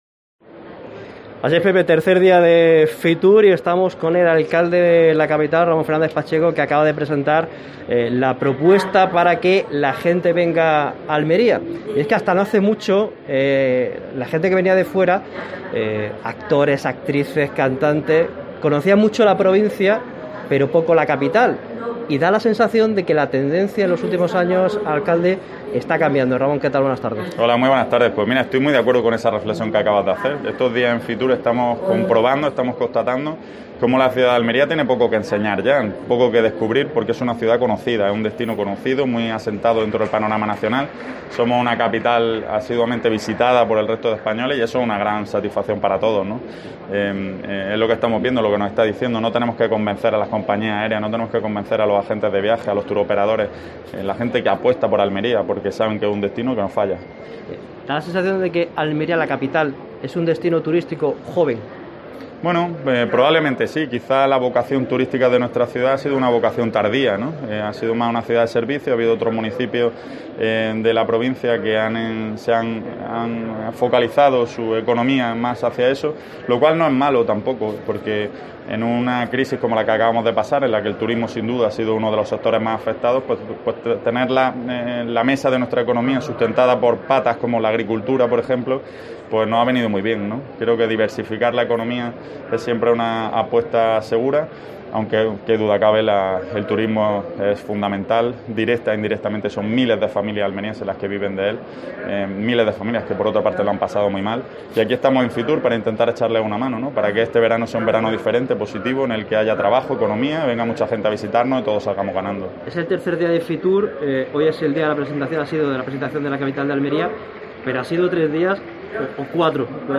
Así lo ha expresado el alcalde de Almería en el tercer, y último, programa especial de COPE Almería desde FITUR.